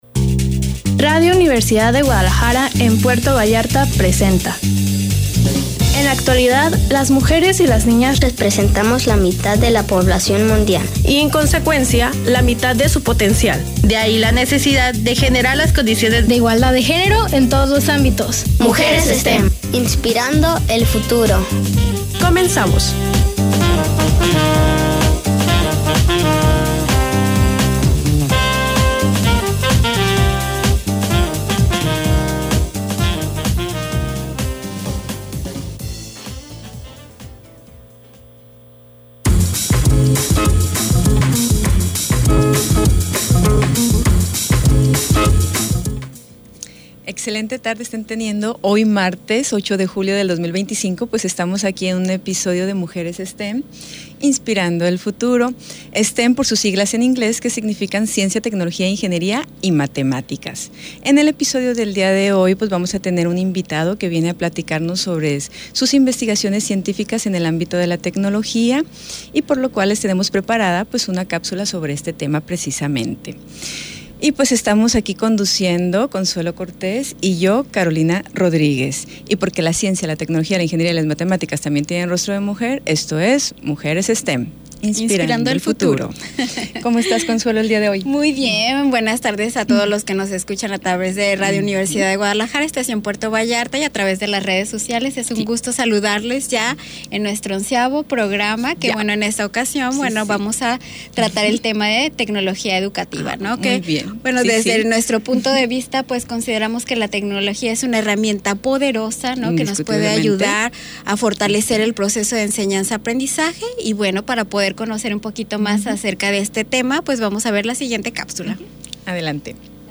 ¡Martes 08 de julio a las 7:00 pm!En esta emisión hablaremos sobre Tecnología Educativa, una herramienta clave para transformar la enseñanza y el aprendizaje desde una perspectiva inclusiva e innovadora. Sintonízanos por Radio Universidad Puerto Vallarta 104.3 FM También en vivo por Facebook Live
¡Acompáñanos en esta conversación sobre educación, tecnología y el futuro del aprendizaje!